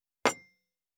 226,机に物を置く,テーブル等に物を置く,食器,グラス,
コップ